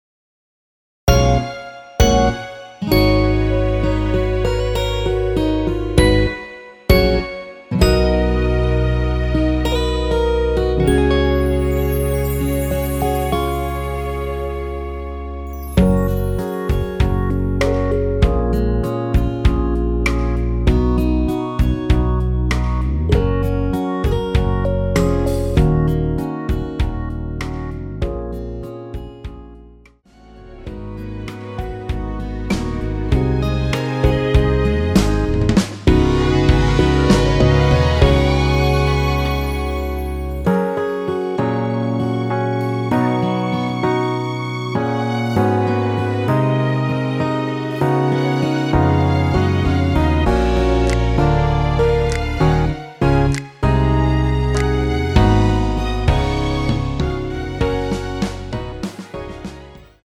원키에서(+4)올린 1절후 후렴으로 진행되게 편곡된 MR입니다.
F#
앞부분30초, 뒷부분30초씩 편집해서 올려 드리고 있습니다.
중간에 음이 끈어지고 다시 나오는 이유는